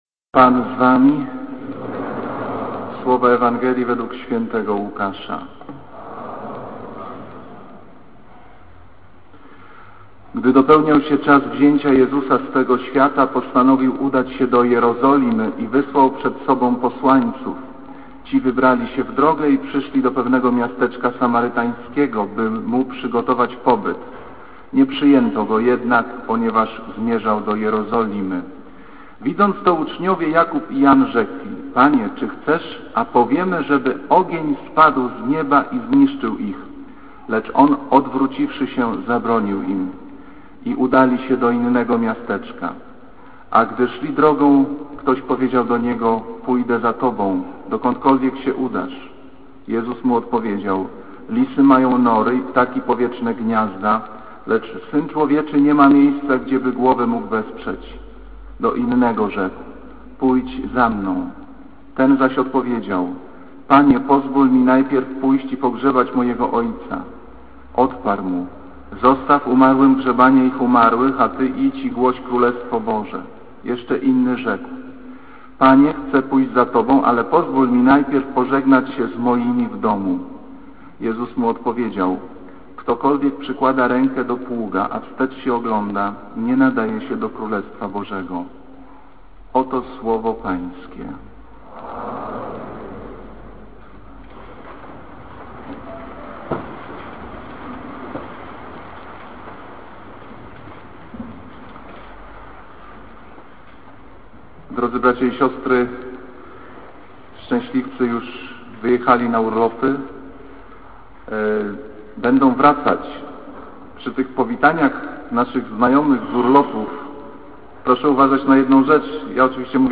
Kazanie z 1 lipca 2007r.
niedziela, godzina 15:00, kościół św. Anny w Warszawie « Kazanie z 24 czerwca 2007r.